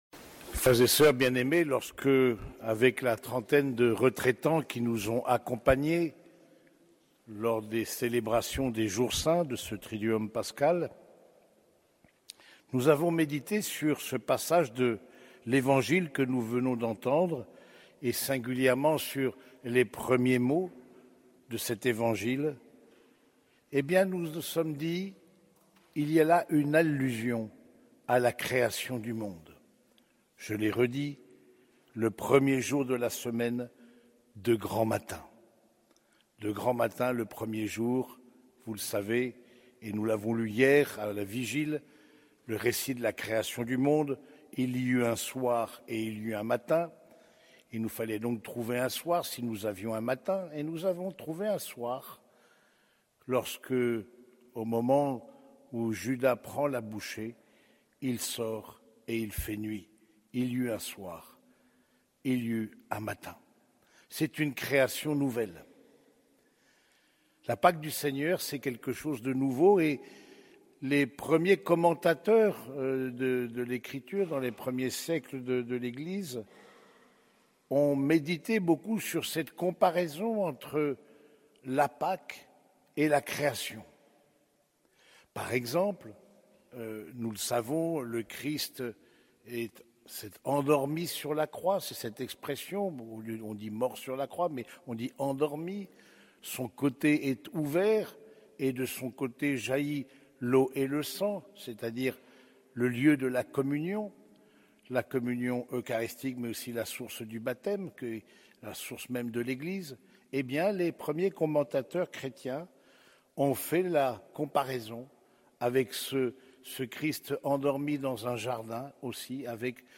Homélie du Dimanche de Pâques